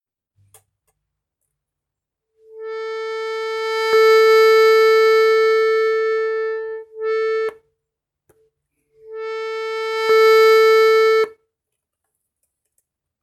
Lautstärkesprung an einem Ton bei zunehmendem Druck
Hallo Alle An meinem frisch erworbenen Adria habe ich etwas seltsames festgestellt: Das A im 8-Fuss-Register macht einen komischen Sprung in der Lautstärke, wenn ich zuerst mit minimalem, anschliessend immer stärkerem Druck spiele.